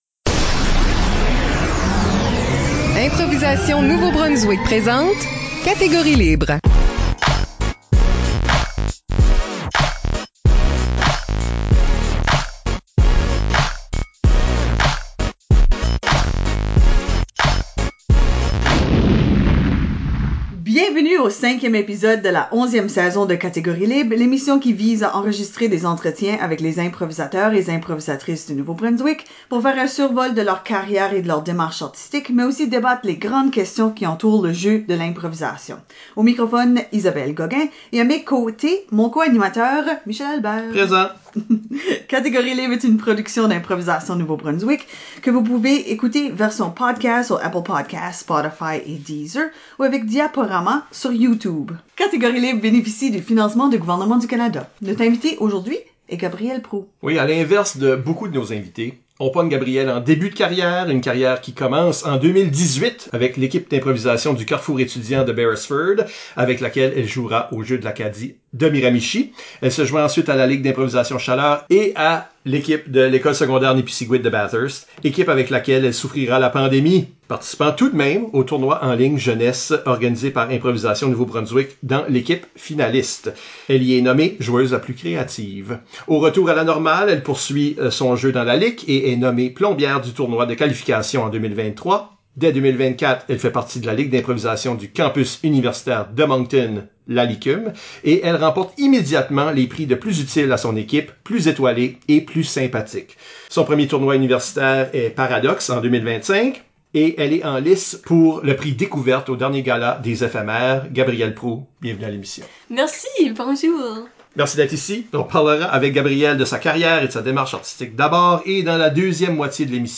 Pour une onzième saison, Catégorie Libre présente une série d’entretiens avec les improvisateurs et improvisatrices de la province pour parler d’eux et des grandes questions qui entourent l’improvisation.